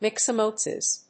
音節myx･o･ma･to･sis発音記号・読み方mɪ̀ksəmətóʊsɪs